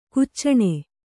♪ kuccaṇe